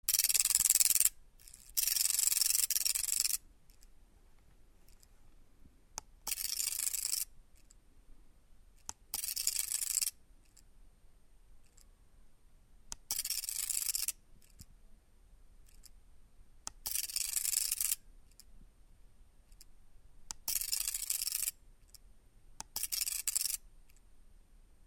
• Napište název pomůcky, jejíž charakteristický zvuk zazněl v ukázce. Jde o poměrně novou pomůcku na našem trhu, která oproti konkurentům přišla se zcela novou technologií, díky níž je daná pomůcka cenově dostupnější, jak je ale z ukázky slyšet, tak i hlasitější než její předchůdci.